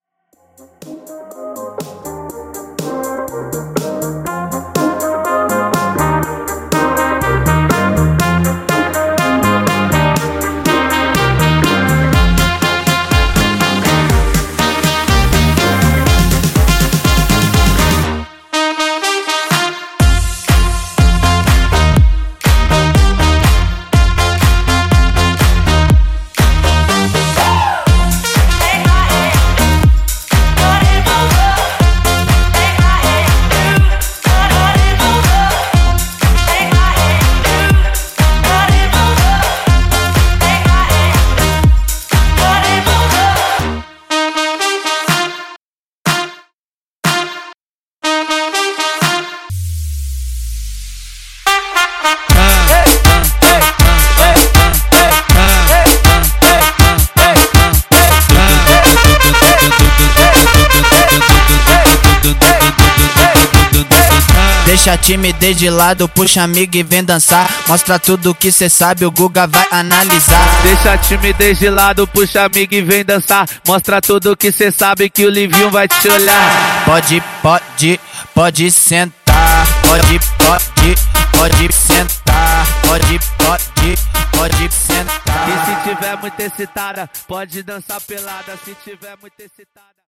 Club Extended Reboot)Date Added